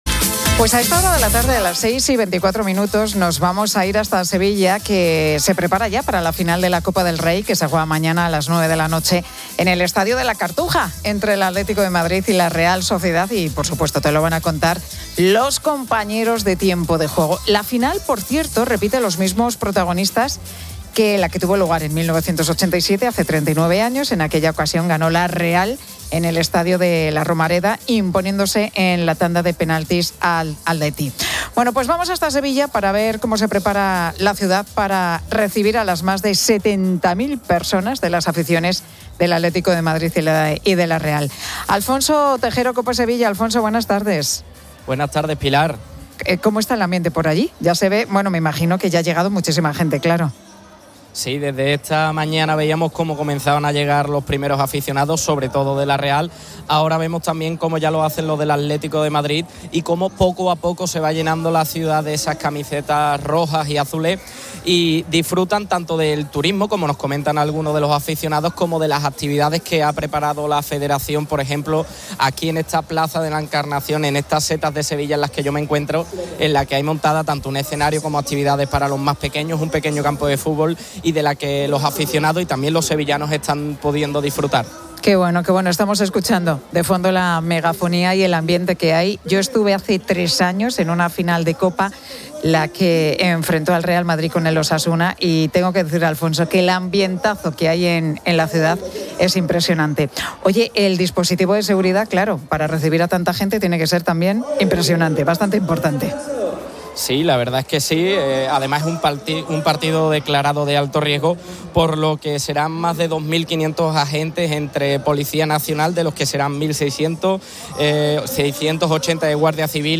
En una intervención en el programa 'La Tarde' de COPE con Pilar García Muñiz, el experto ha señalado que este fin de semana estará marcado por temperaturas veraniegas que coincidirán con la celebración de la final de la Copa del Rey en Sevilla.